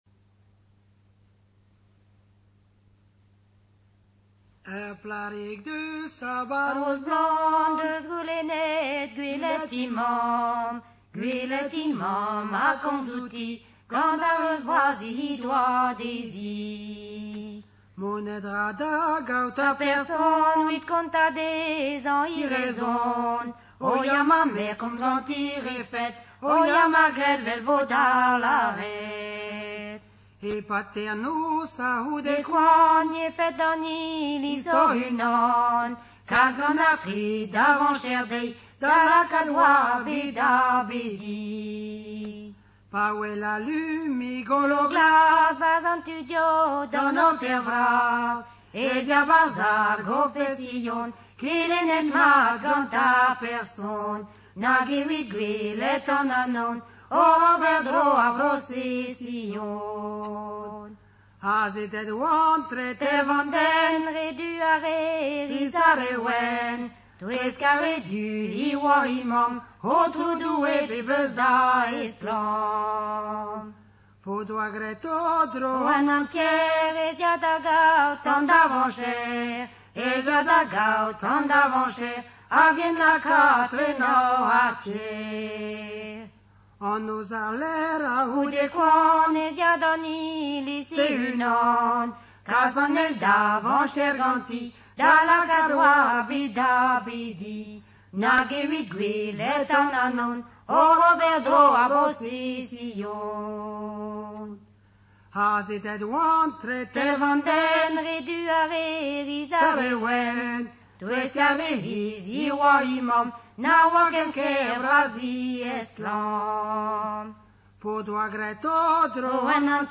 Chanté par les "Kanerien Bro-Dreger" en 1988
kanerien_bdreger_das1988.mp3